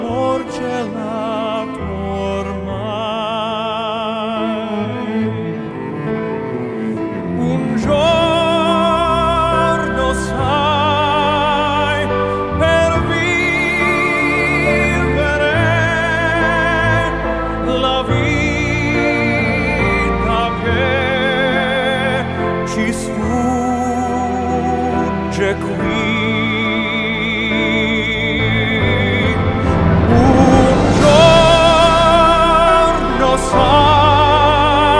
Жанр: Поп музыка / Рок / Классика